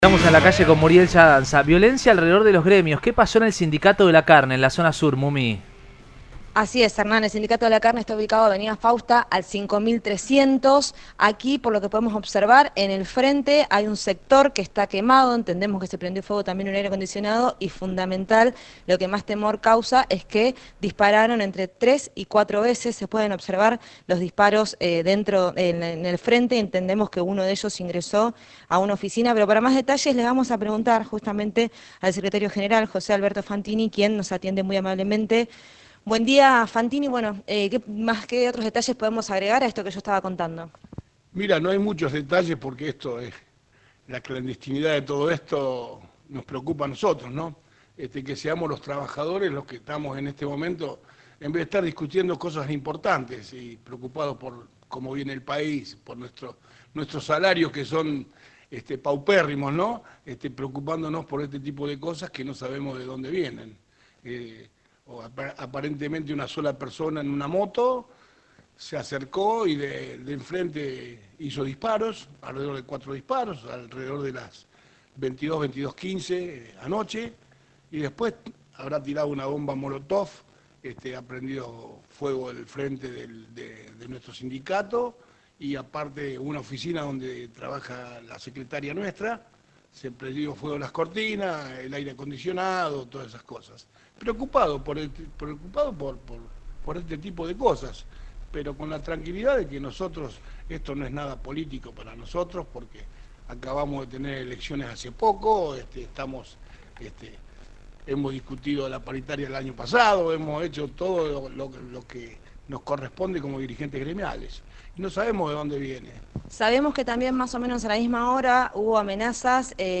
En diálogo con el móvil de Cadena 3 Rosario